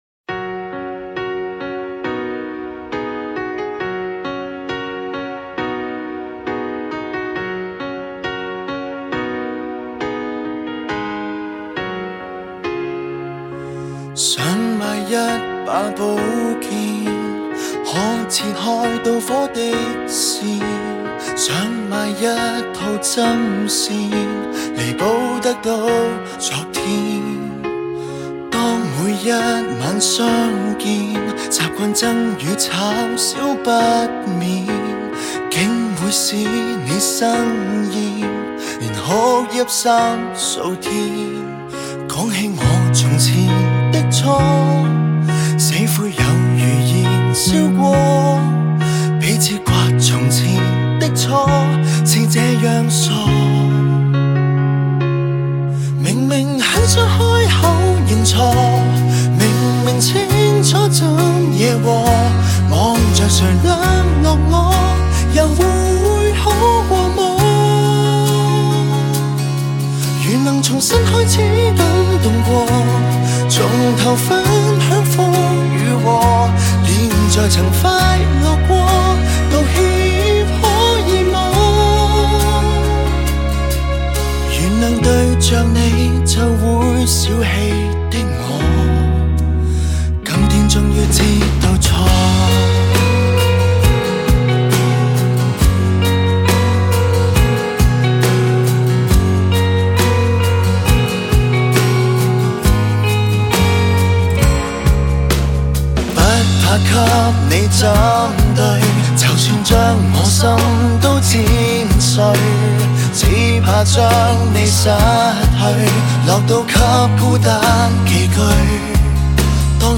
走抒情路线